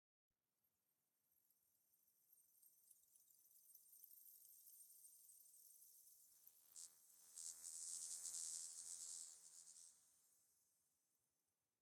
firefly_bush4.ogg